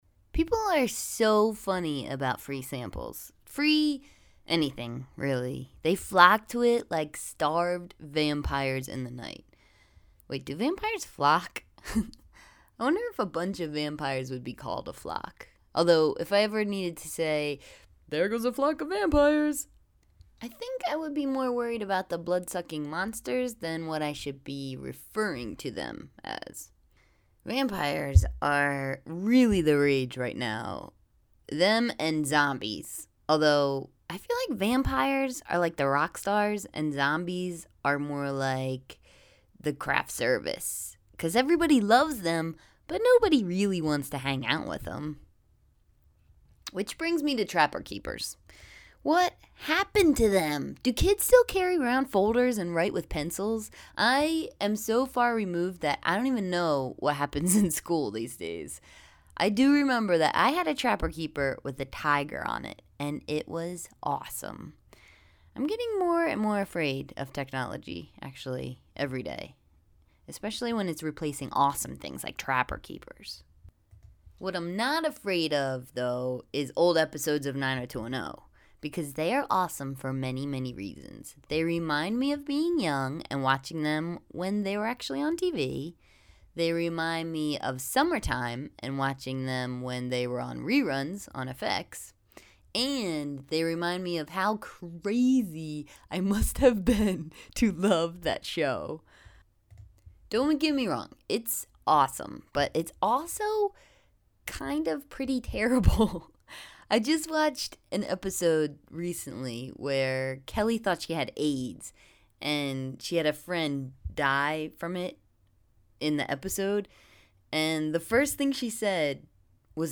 PRESS PLAY TO HEAR ME READ THIS BLOG TO YOU!